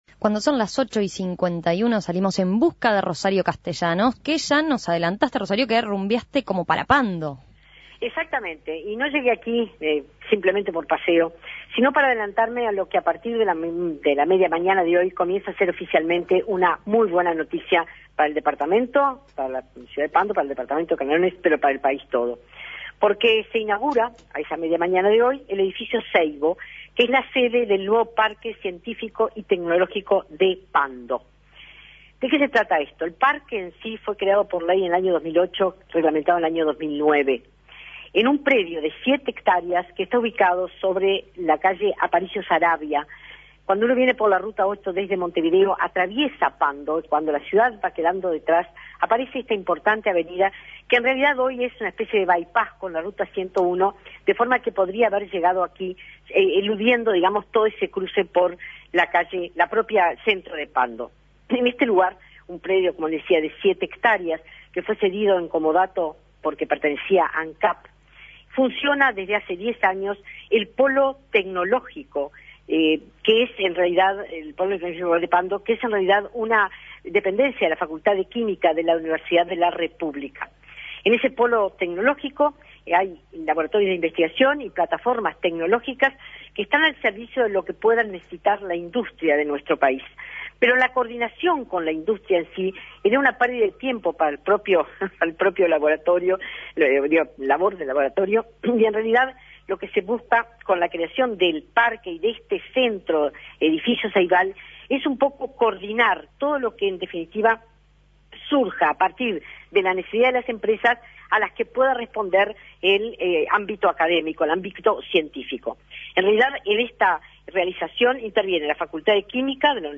Inauguración del Edificio Ceibo, cede del Parque Científico y Tecnológico de Pando